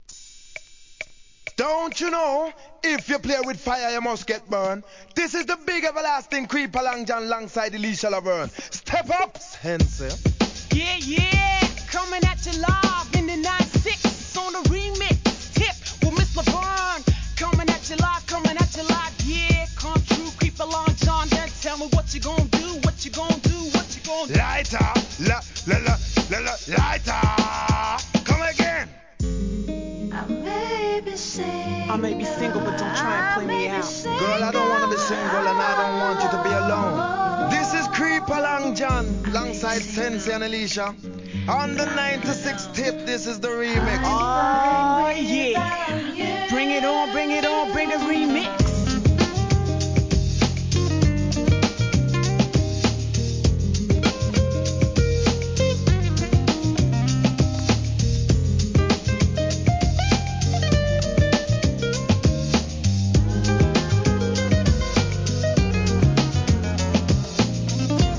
HIP HOP/R&B
'90s ミッドR&Bのロングラン大人気作!